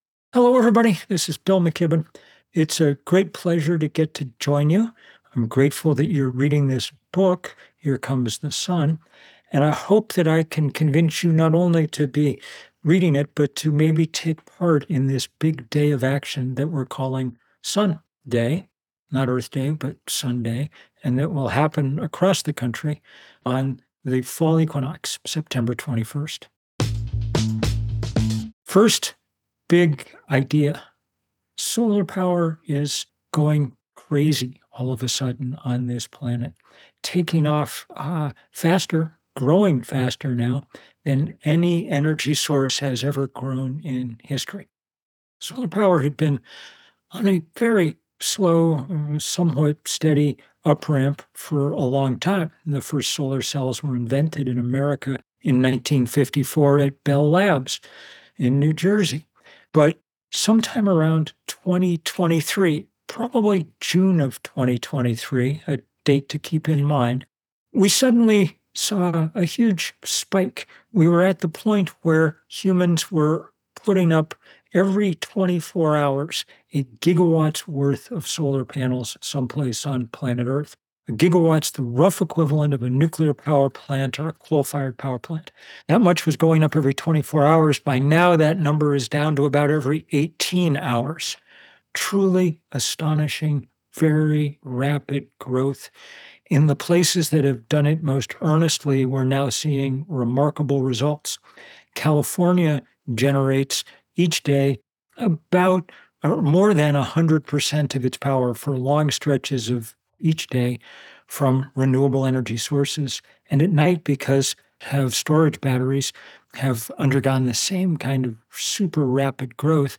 Listen to the audio version—read by Bill himself—below, or in the Next Big Idea App.